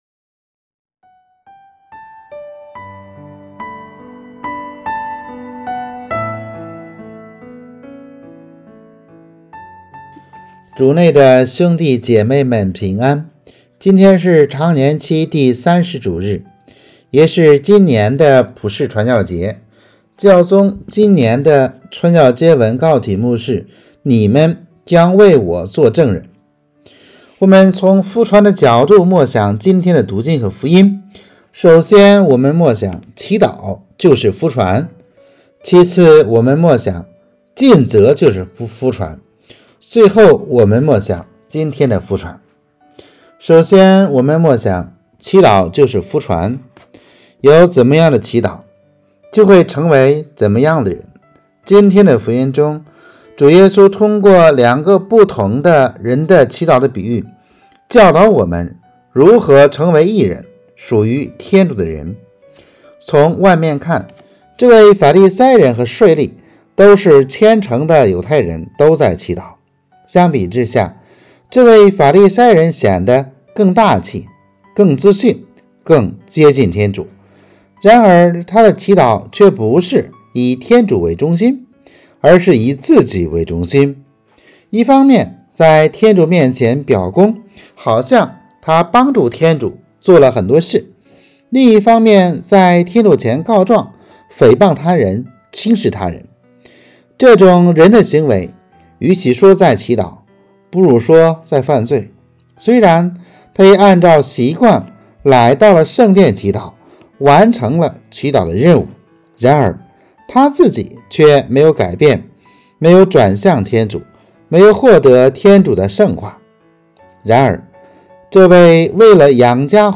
【主日证道】|祈祷会传福（传教节）